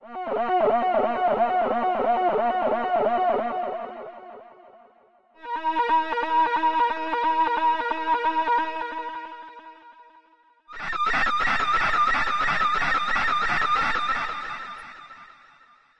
合成器 " Photone调频管
描述：采样的补丁（3个音符）来自我创建的Reaktor Photone。有两个正弦振荡器，每个振荡器都对其他振荡器进行频率调制。还添加了一点内置的合唱和延迟。
Tag: 呐喊 Reaktor的 Photone 倾斜 延音 合成器 管道 FM 调频